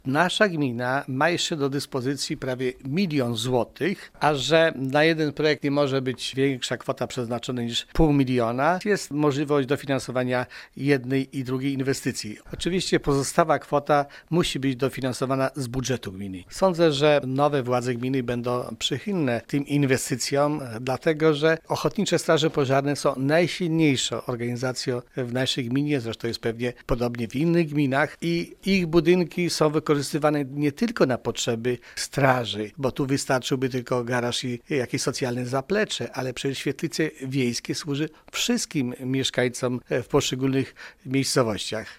Gmina już przygotowała projekt obydwu inwestycji, ale o tym czy i kiedy zostaną rozpoczęte budowy zadecydują nowe władze – mówi zastępca wójta Gminy Łuków Wiktor Osik.
„Takie możliwości daje Lokalna Grupa Działania” - tłumaczy Wiktor Osik: